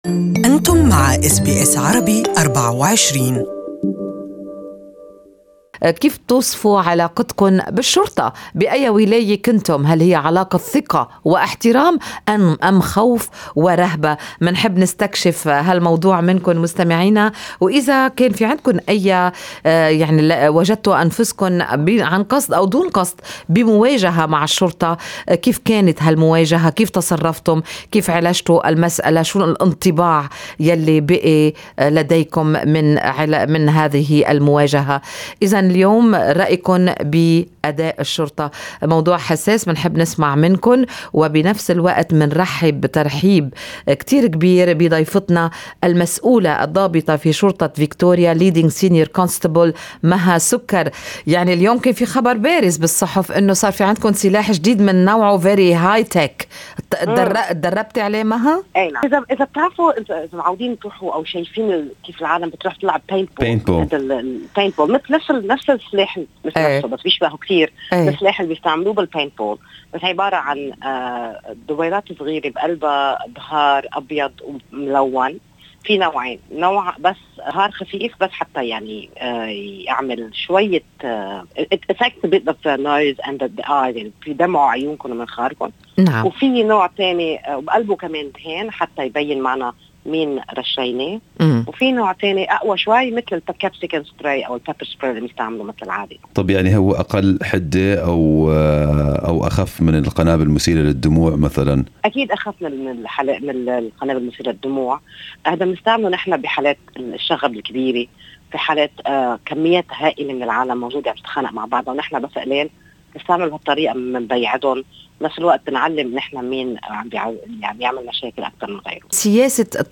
Good Morning Australia interviewed leading Senior Constable